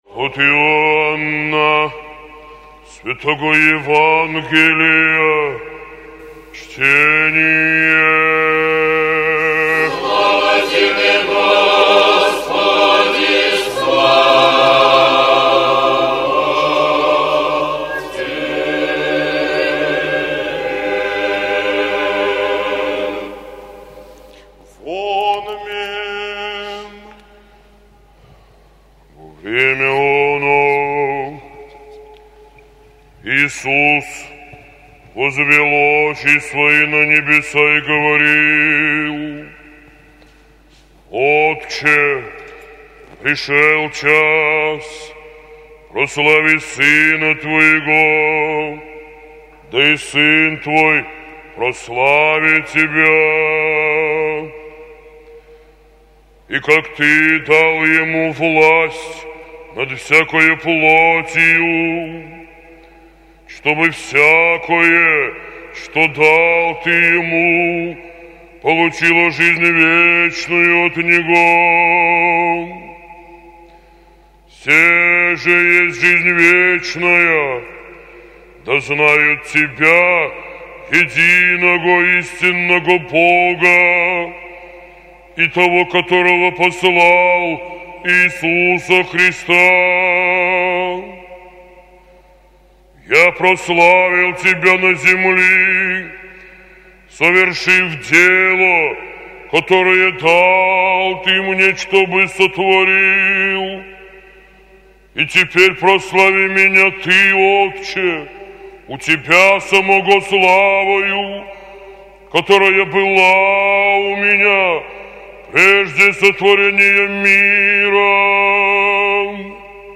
ЕВАНГЕЛЬСКОЕ ЧТЕНИЕ НА литургии